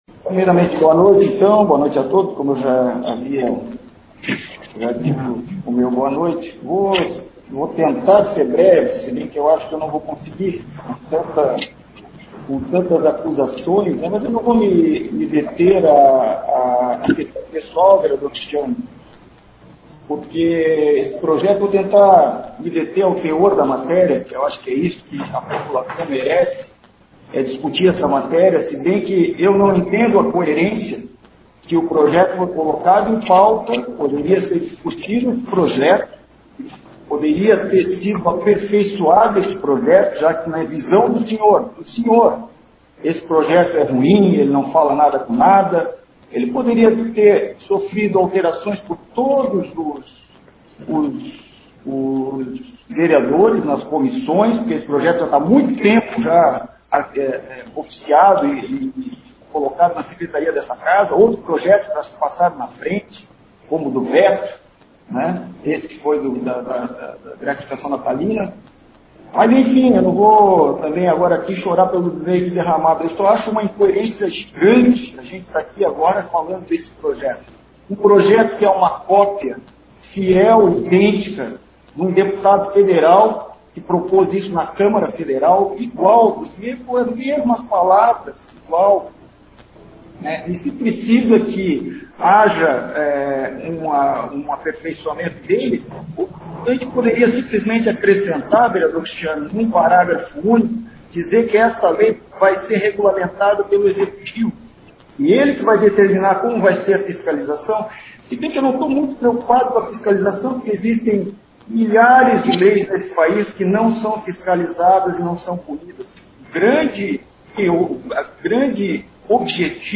Na noite de terça-feira, 15 de dezembro, ocorreu mais uma sessão ordinária da Câmara Municipal de Guabiruba remotamente e foi presidido pela vereadora Rosita Kohler (Progressistas) contando com as participações dos demais parlamentares.
Atendendo convocação do vereador Felipe Eilert dos Santos (PT) participou da reunião Patrícia Heiderscheidt, secretária de Saúde de Guabiruba, momento que apresentou informações sobre os atendimentos relacionados ao coronavírus (Covid-19).